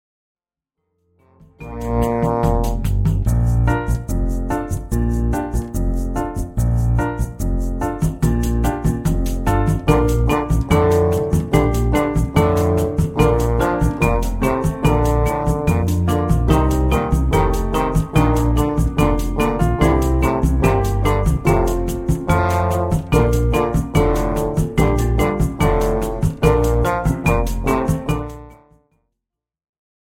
• für 1-2 Posaunen
Sehr leichte bis leichte Weihnachtslieder
Klangbeispiel